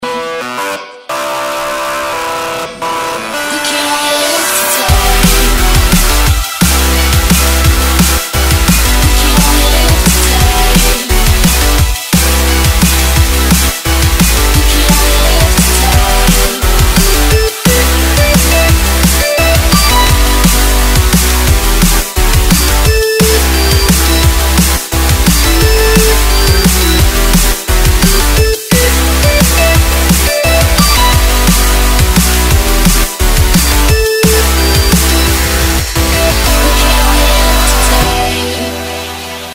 • Качество: 128, Stereo
drum&bass